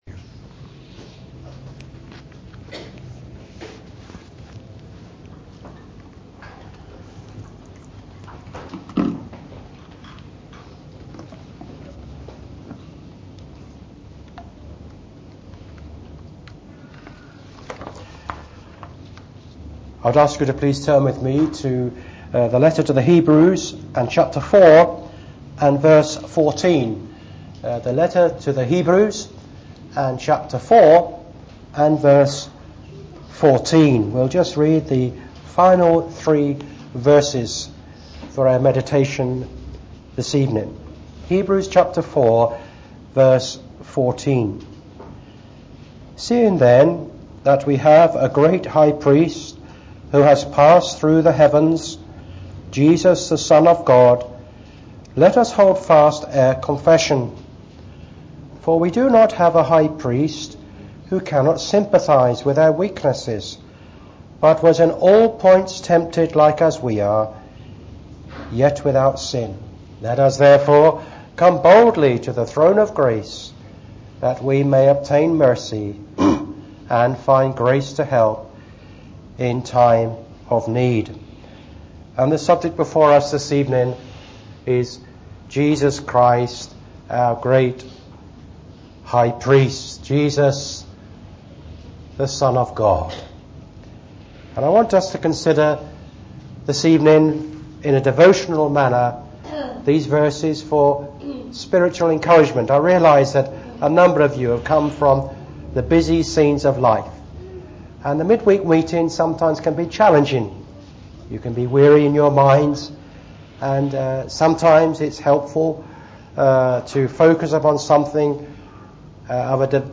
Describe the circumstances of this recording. A Special Meeting was held on the 12th of August 2018 during the Wednesday Evening